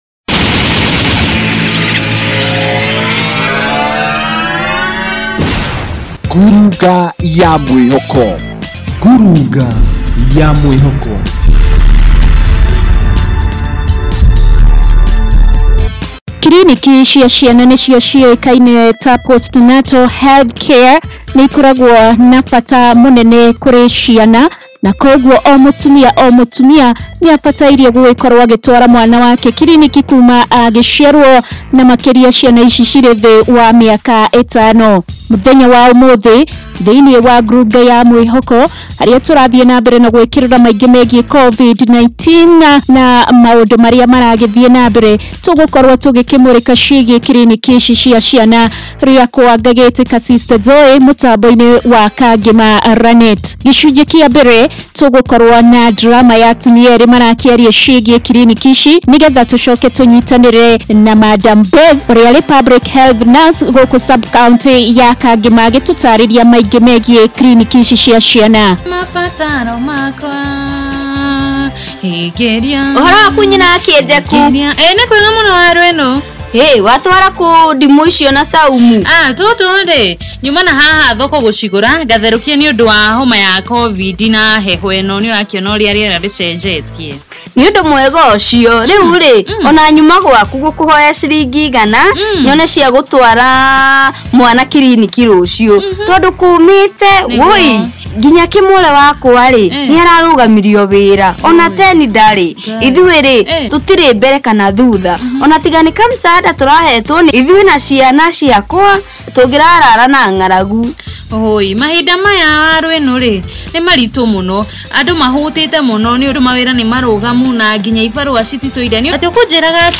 KANGEMA RANET. FEATURE.mp3